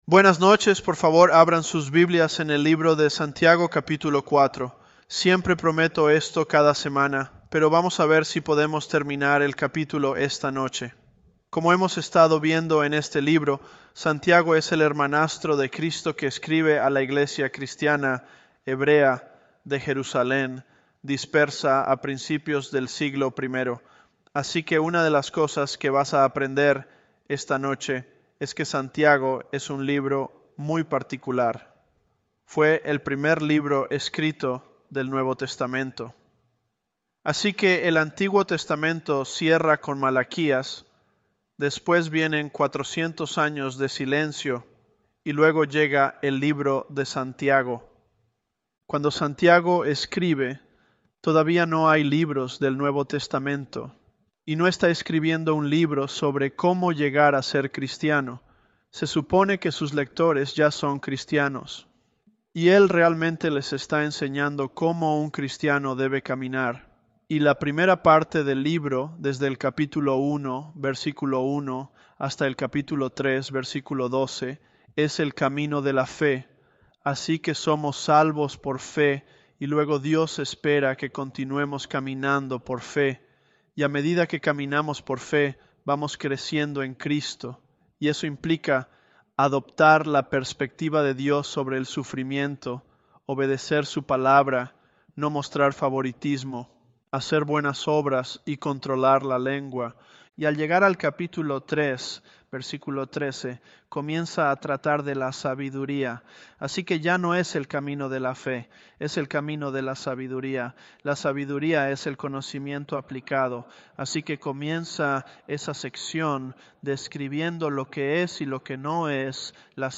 ElevenLabs_James025.mp3